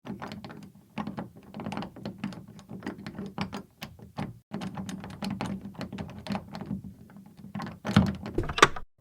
Lock Picking a Door
Foley
Lock Picking a Door is a free foley sound effect available for download in MP3 format.
yt_mjW9Kxlgsoc_lock_picking_a_door.mp3